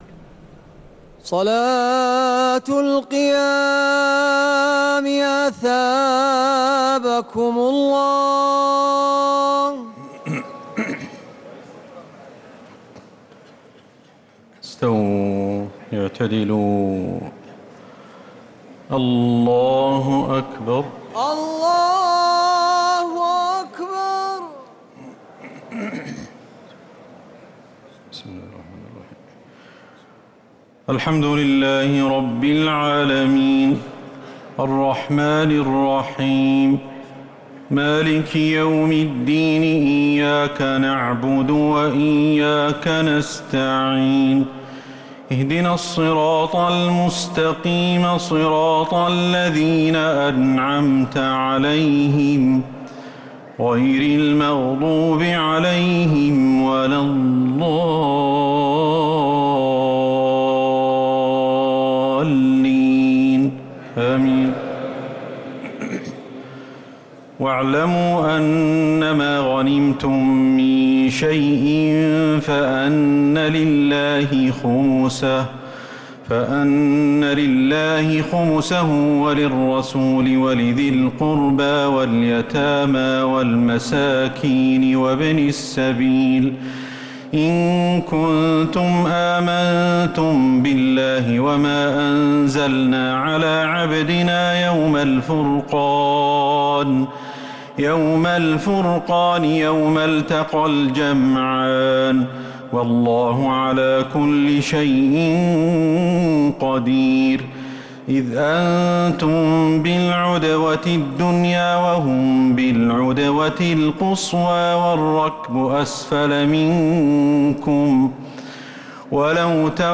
تراويح ليلة 13 رمضان 1446هـ من سورتي الأنفال (41) التوبة (1-27) | taraweeh 13th niqht Surah Al-Anfal and At-Tawba 1446H > تراويح الحرم النبوي عام 1446 🕌 > التراويح - تلاوات الحرمين